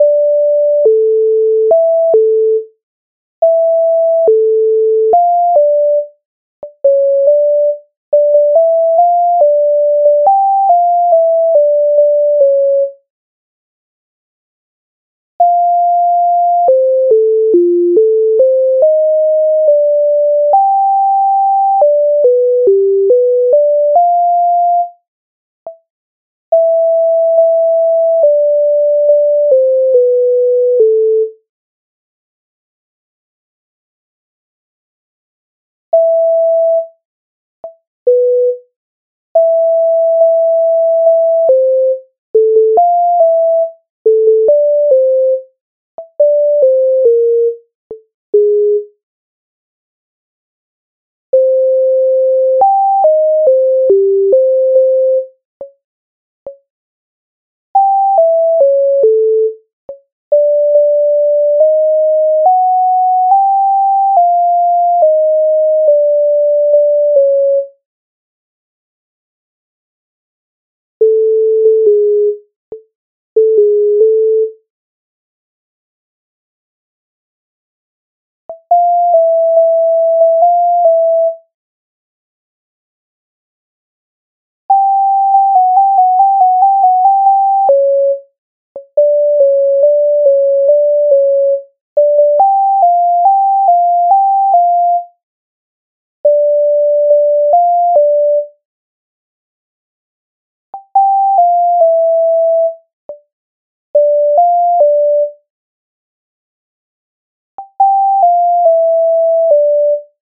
MIDI файл завантажено в тональності d-moll